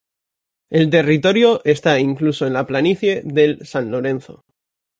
te‧rri‧to‧rio
/teriˈtoɾjo/